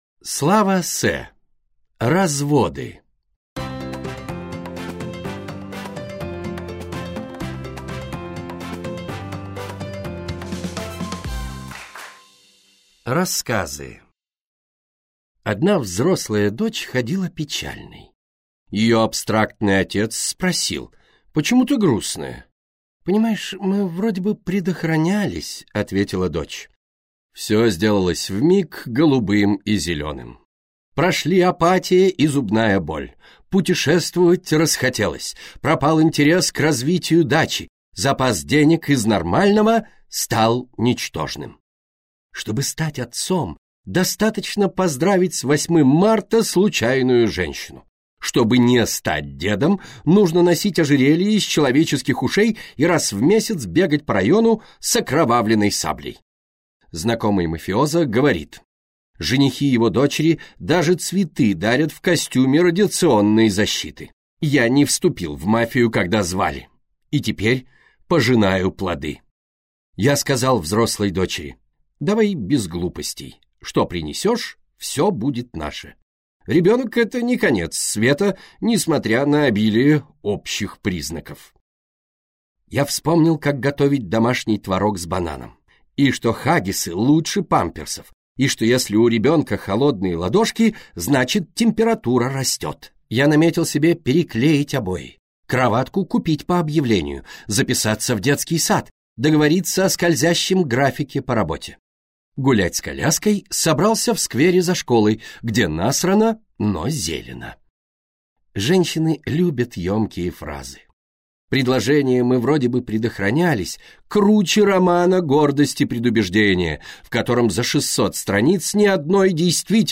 Аудиокнига Разводы (сборник) | Библиотека аудиокниг